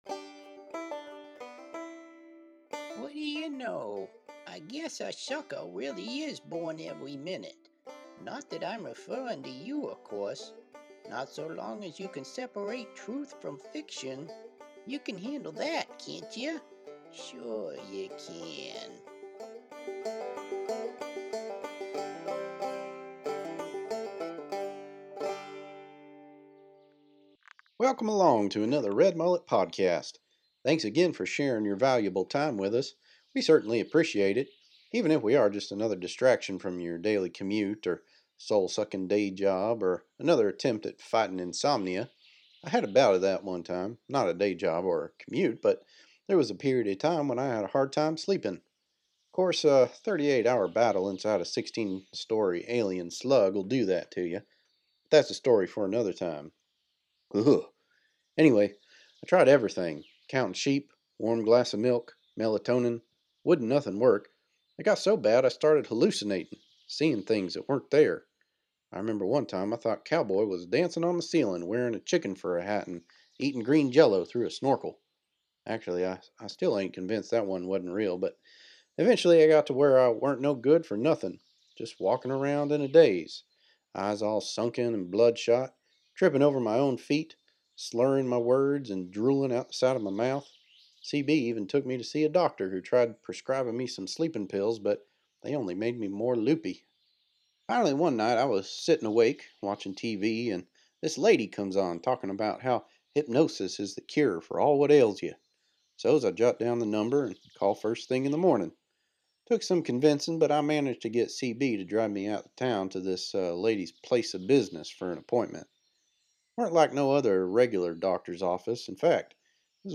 Red Mullet interviews the podcast’s first female superhero, Piggy Tails.